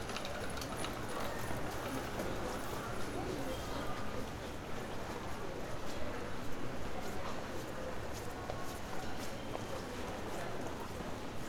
Paris_street2.L.wav